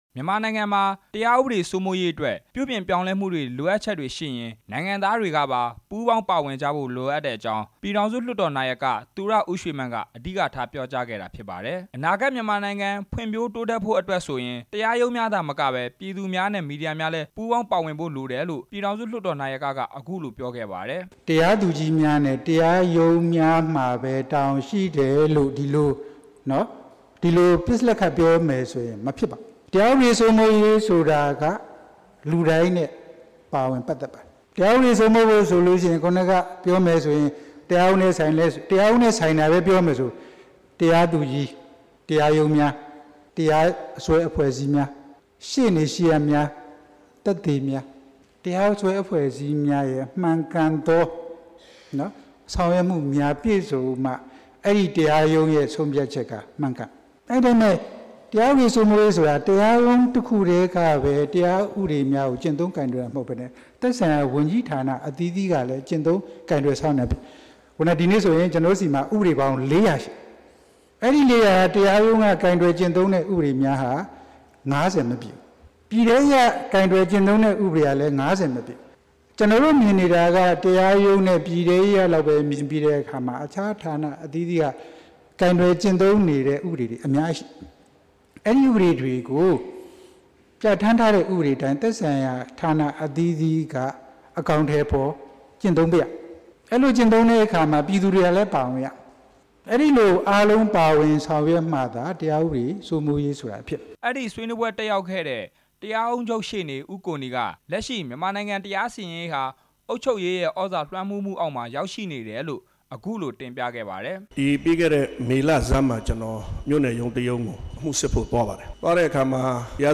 ဒီနေ့ ရန်ကုန်တက္ကသိုလ် စိန်ရတုခန်းမမှာကျင်းပတဲ့ ဥပဒေပြုရေးနဲ့ တရားဥပဒေ စိုးမိုးရေးဆိုင်ရာ ဆွေးနွေးပွဲ မှာ သူရဦးရွှေမန်းက အခုလို တိုက်တွန်းပြောဆိုခဲ့တာပါ။